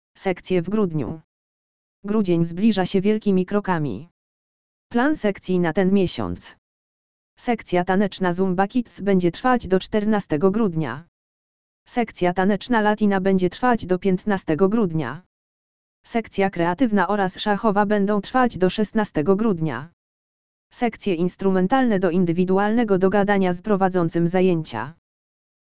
audio_lektor_sekcje_w_grudniu_!.mp3